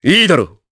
Riheet-Vox_Happy4_jp.wav